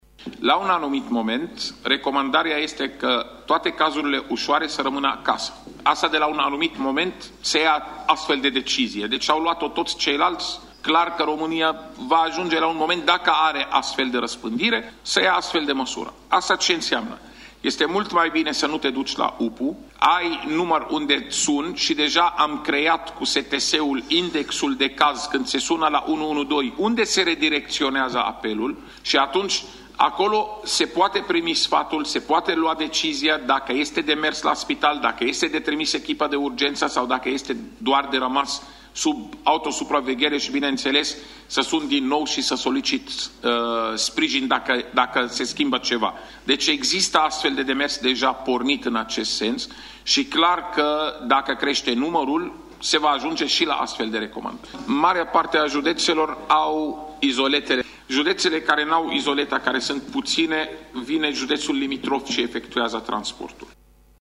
Declarația a fost făcută de secretarul de stat în MAI, dr. Raed Arafat, în cadrul audierilor din Comisia de Sănătate din Parlament.